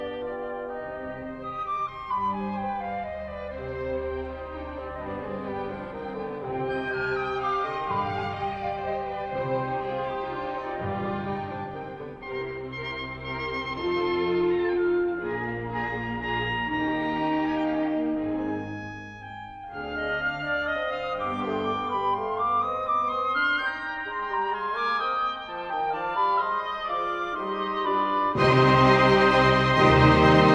Arthur Rubinstein, Mozart, Haydn, Beethoven, Schumann, Brahms, Liszt, Rachmaninov
Concerto pour piano et orchestre Nʻ24, KV 491, ut mineur
"enPreferredTerm" => "Musique classique"